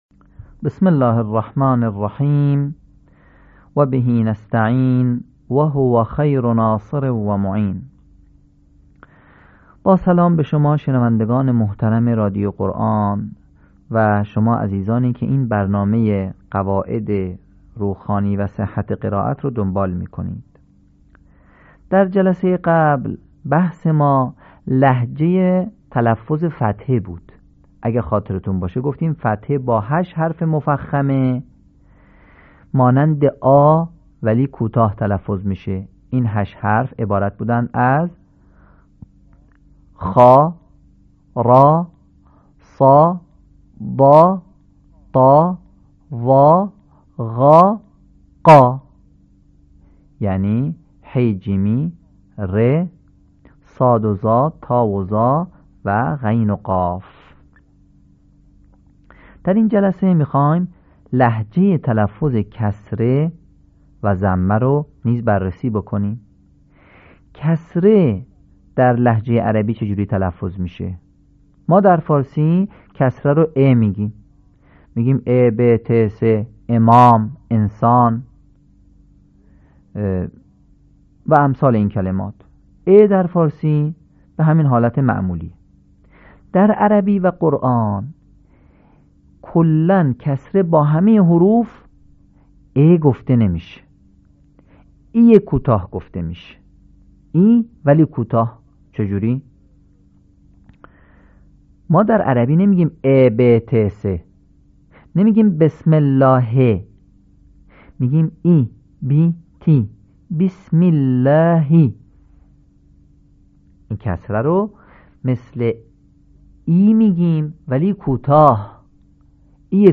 صوت | آموزش روخوانی «لهجه حرکت فتحه»